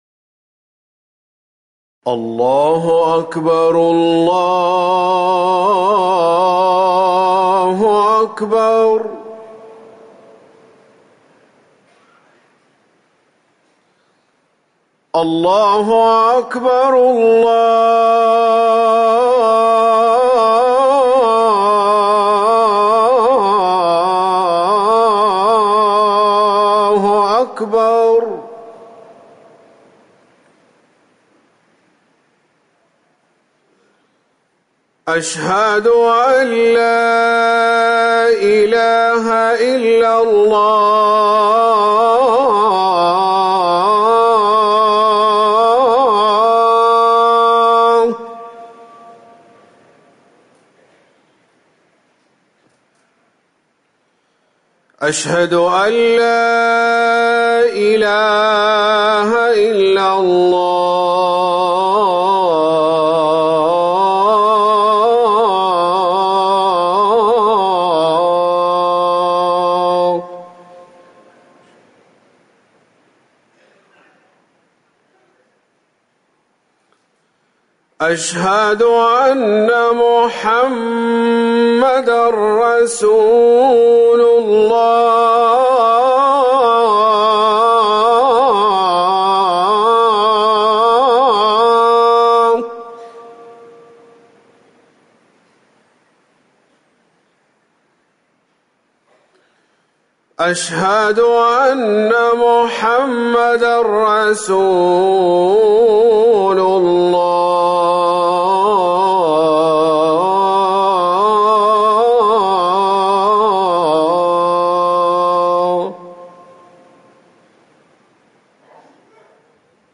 أذان الظهر
تاريخ النشر ٢٧ محرم ١٤٤١ هـ المكان: المسجد النبوي الشيخ